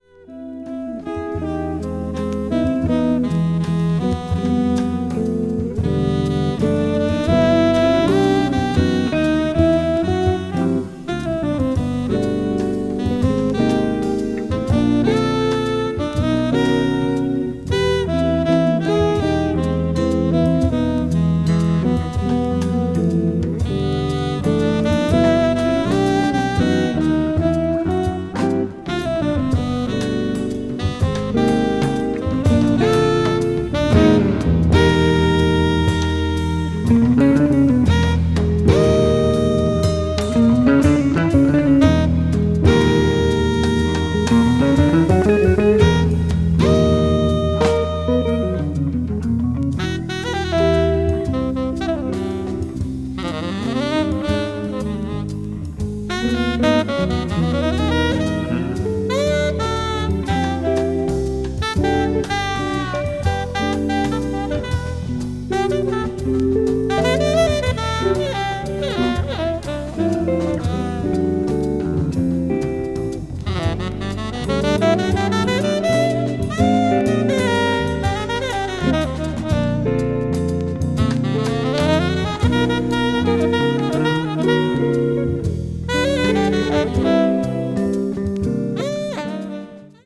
contemorary jazz   deep jazz   jazz rock